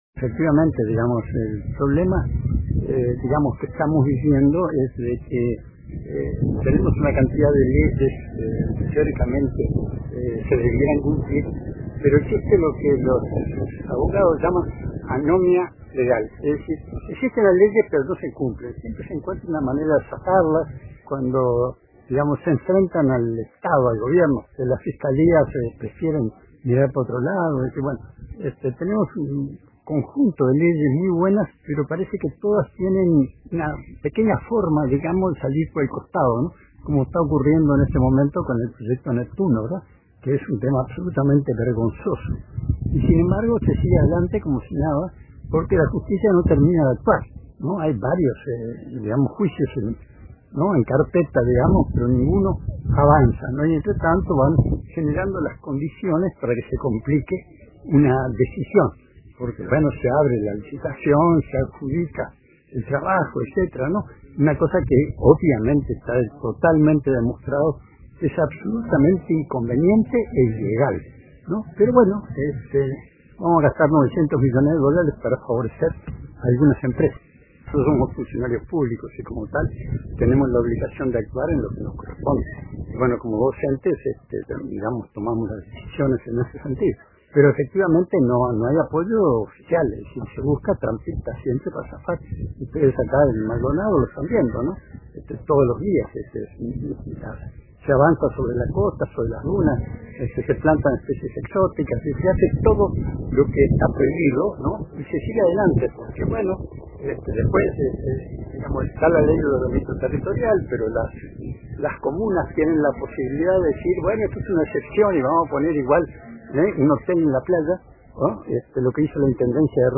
En una entrevista con RADIO RBC